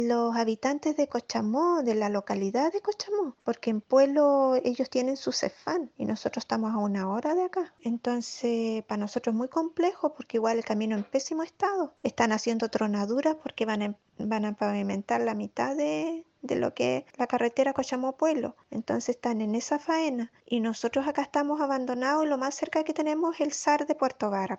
Al respecto, una usuaria del recinto, quien prefirió ocultar su identidad, indicó su preocupación ante esta situación.